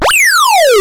Checkpoint.wav